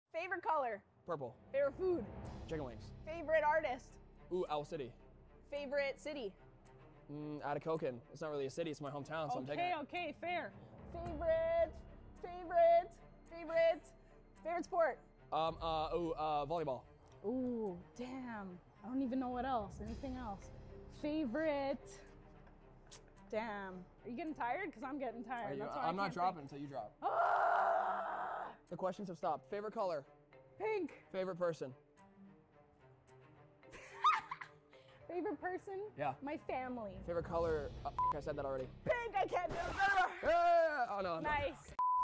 rapid fire interview